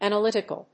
音節àn・a・lýt・i・cal 発音記号・読み方
/‐ṭɪk(ə)l(米国英語)/
フリガナアナリティカル